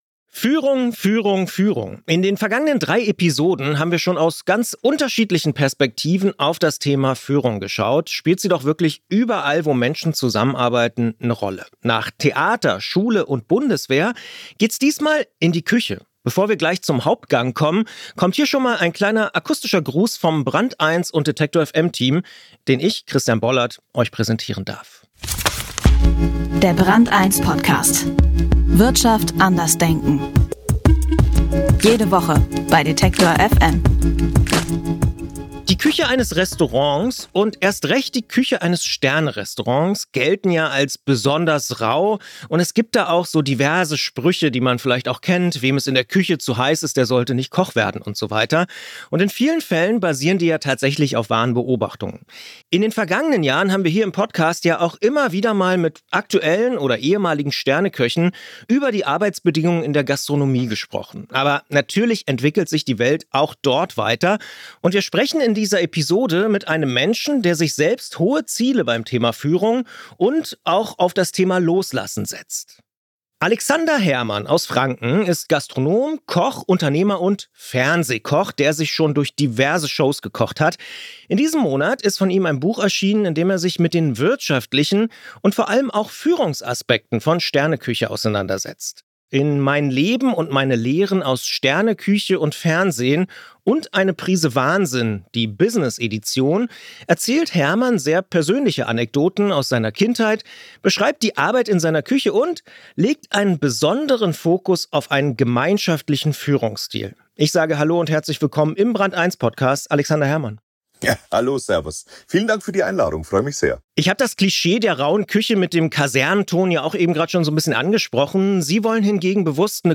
Alexander Herrmann ist Gastronom, Unternehmer und Fernsehkoch. Ein Gespräch über Verantwortung, Loslassen und Fehlerkultur.